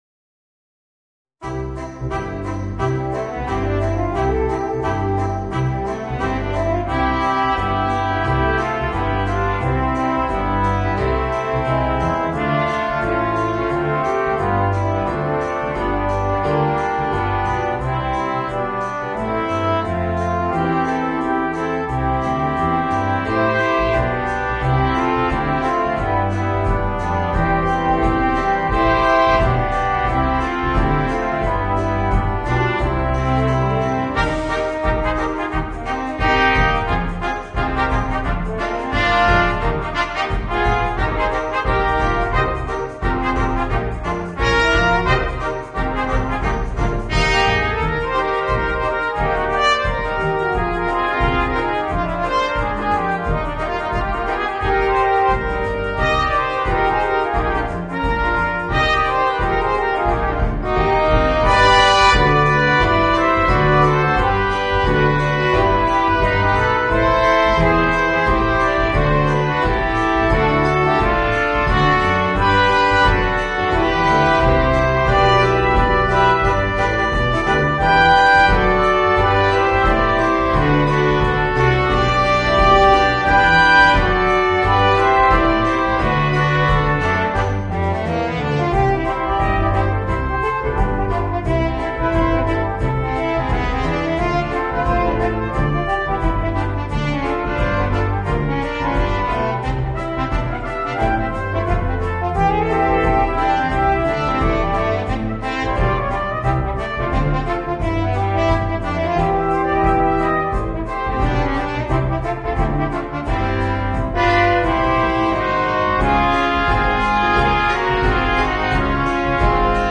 Voicing: 3 Trumpets and Trombone